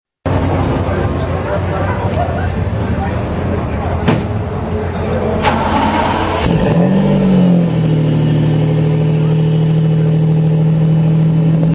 Starting The Ignition